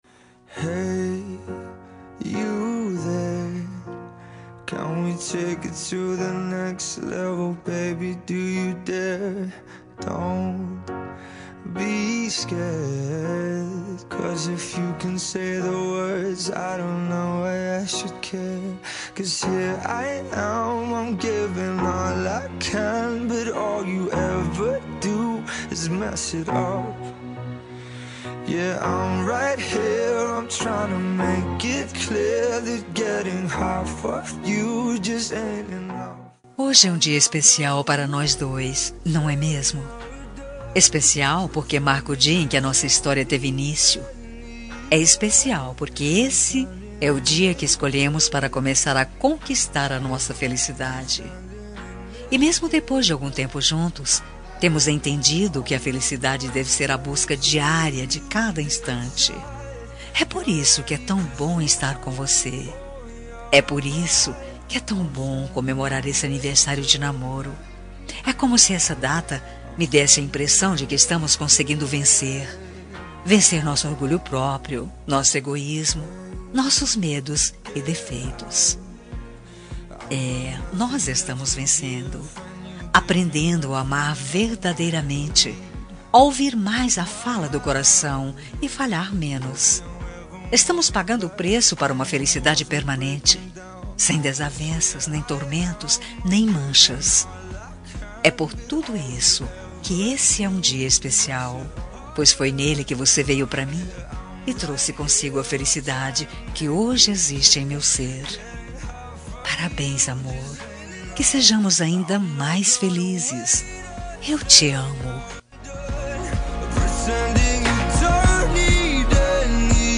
Telemensagem Aniversário de Namoro – Voz Feminina – Cód: 8096 – Linda.
8096-aniv-namoro-fem.m4a